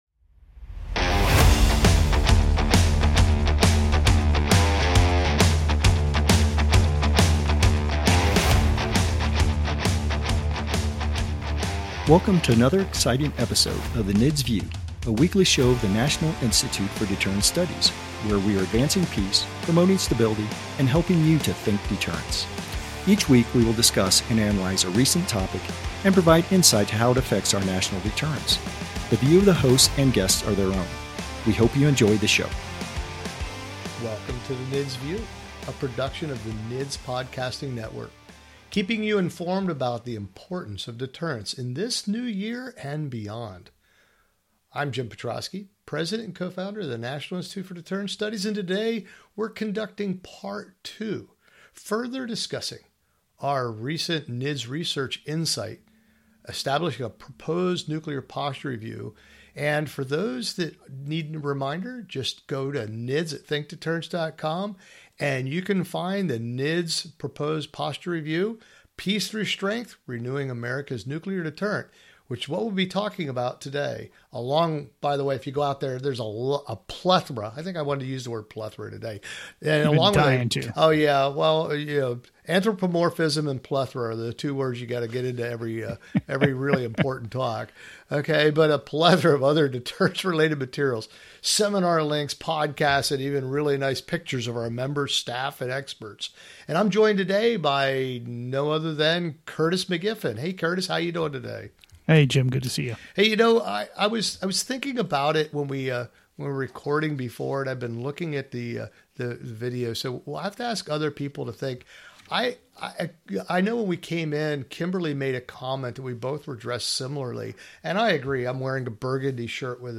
The NIDS View is meant to give listeners inside access to the thoughts and ideas of the member-practitioners of NIDS including our Fellows, Analysts, and Interns. For each episode we pick a current deterrence topic and have a cordial, exciting and sometimes funny discussion.